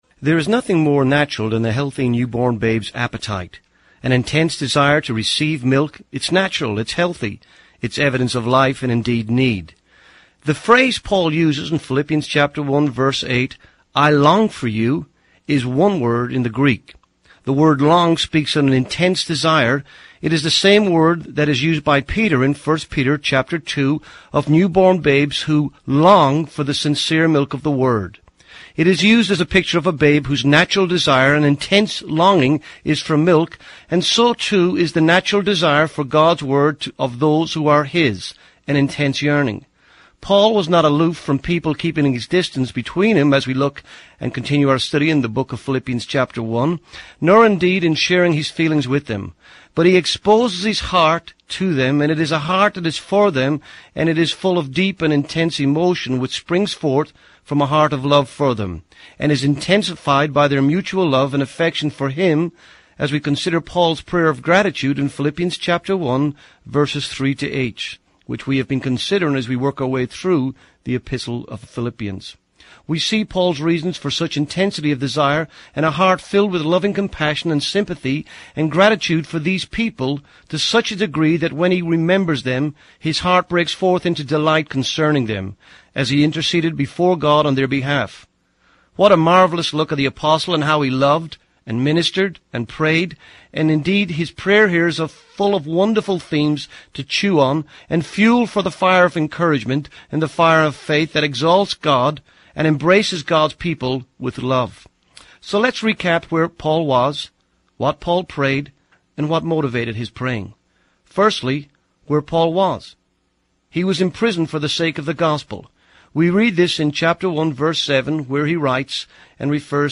This is the twelfth message in the Philippians series.